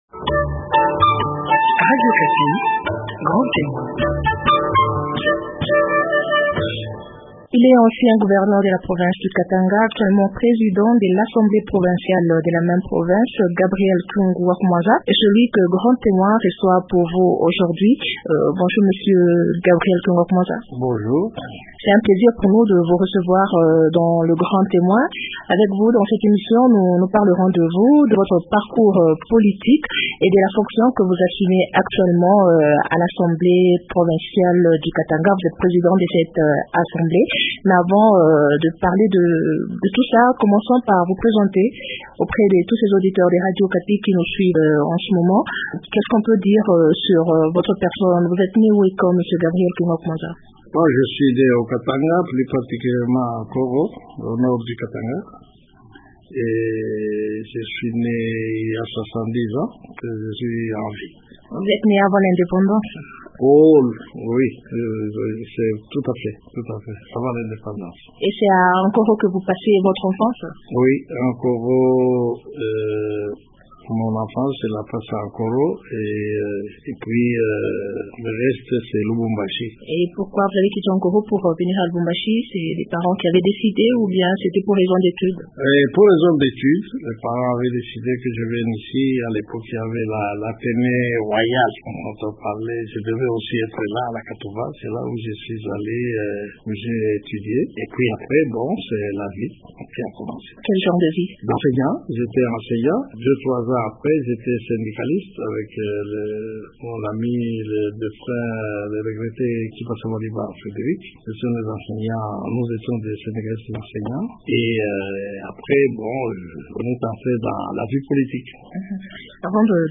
Gabriel Kyungu wa Kumwanza, ancien gouverneur du Katanga et actuel président de l’Assemblée provinciale de cette même province, est l’homme politique que Grand Témoin vous présente.